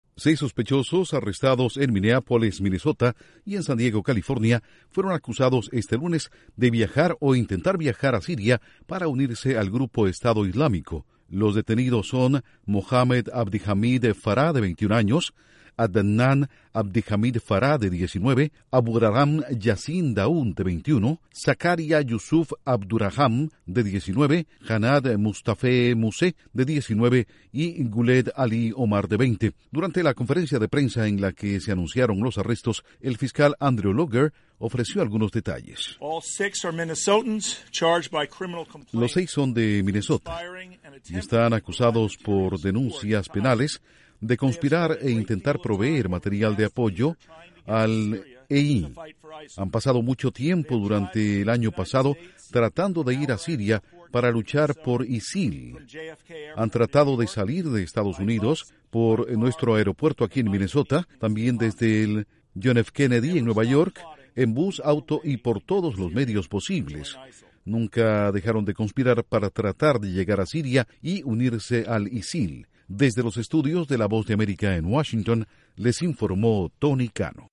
Fiscal General de Minnesota revela la identidad de los seis arrestados en Minneapolis y San Diego por presuntos vínculos con el terrorismo. Informa desde la Voz de América en Washington